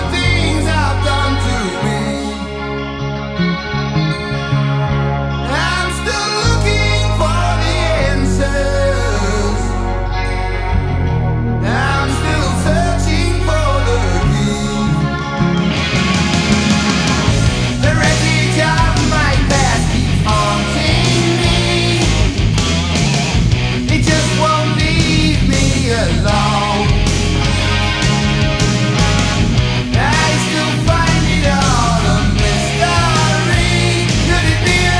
Hard Rock Rock Metal Arena Rock
Жанр: Рок / Метал